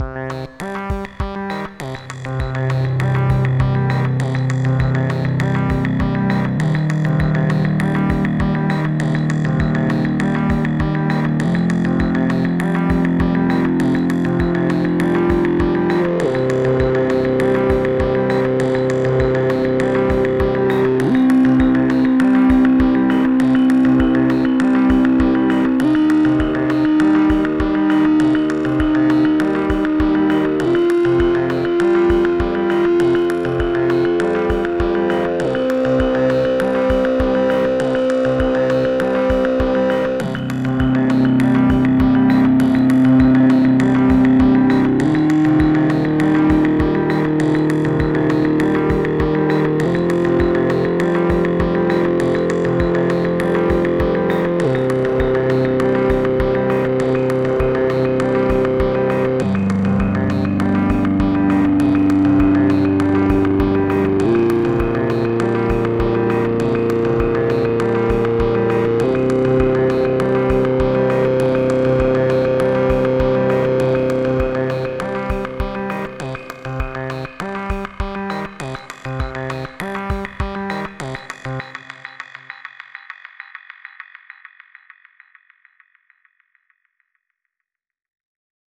Pieza de Ambient Techno
Música electrónica
tecno
melodía
sintetizador